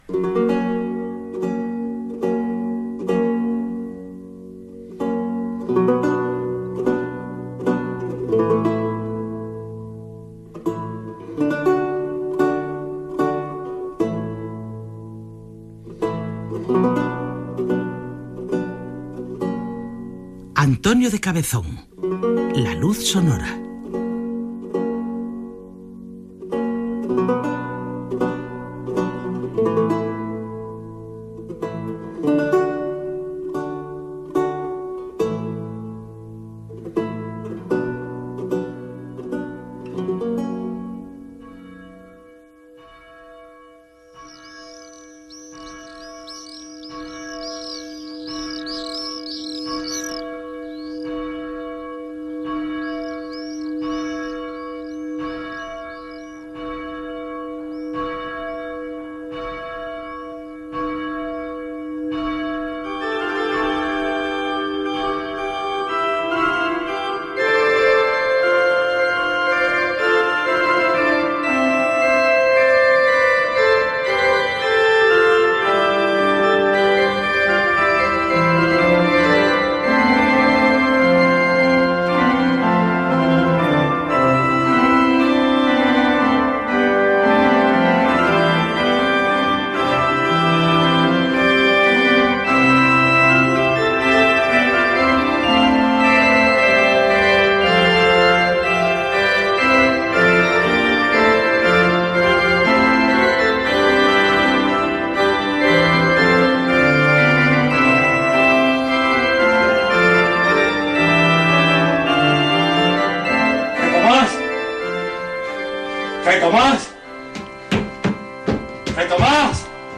Dramàtic sobre la vida del compositor Antonio de Palazón Gènere radiofònic Ficció